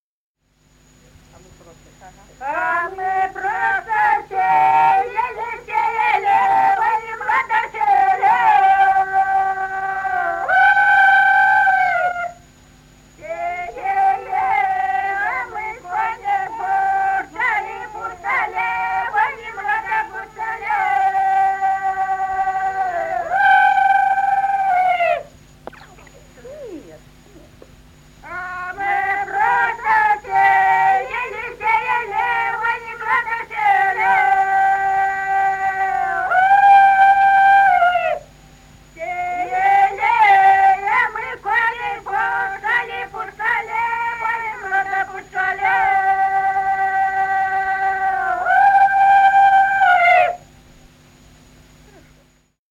Народные песни Стародубского района «А мы просо сеяли», юрьевские таночные.
с. Курковичи.